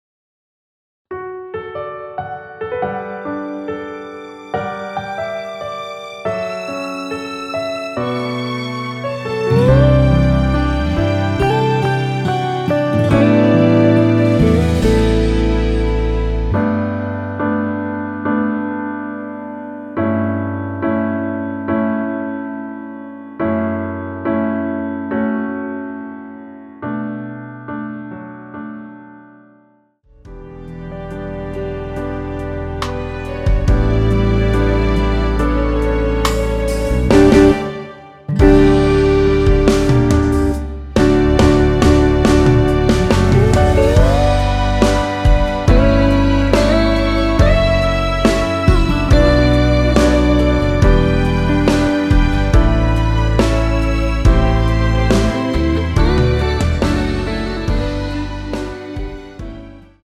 원키에서(-1)내린 (1절앞+후렴)으로 진행되는 MR입니다.
D
앞부분30초, 뒷부분30초씩 편집해서 올려 드리고 있습니다.